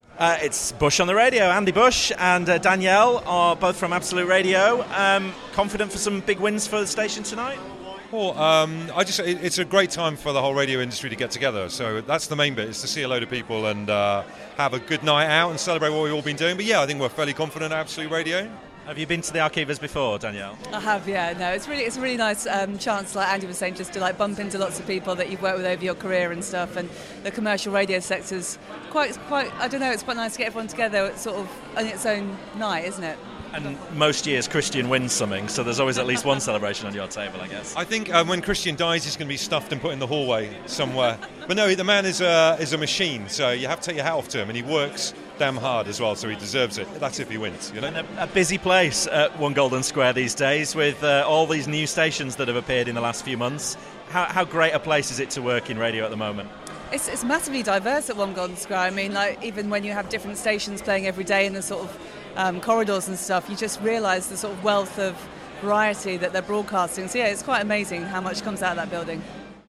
RadioToday Live Interviews